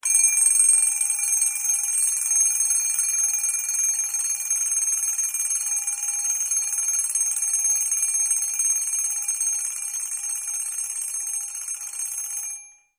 Alarm Clock, Wind - Up, Medium Pitched, Fast Ringing.